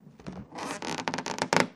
Rubber Stretch